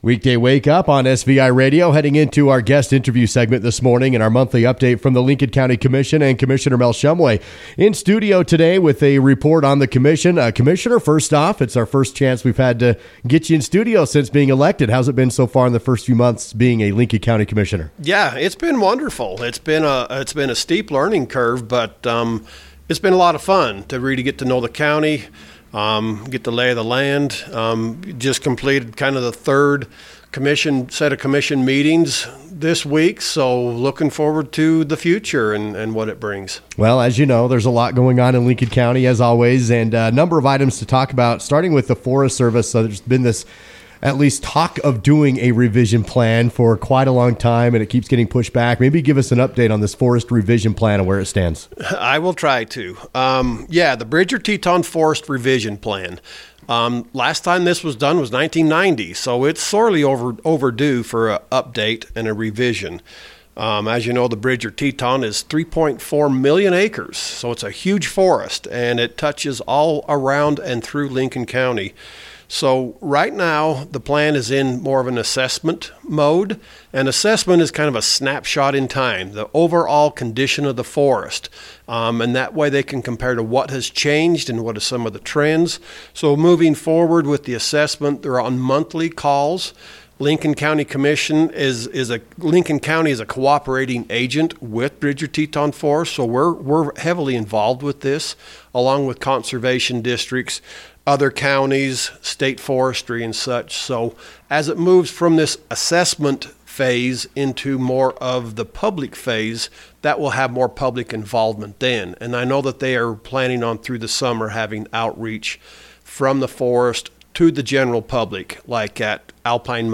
Commissioner Mel Shumway stepped into the SVI Radio studio with the monthly report from the Lincoln County Commission. Shumway discussed the latest in an upcoming Forest Revision Plan with the Bridger-Teton National Forest, trail improvements in southern Lincoln County and plans being made for a semiquincentennial celebration in the summer of 2026.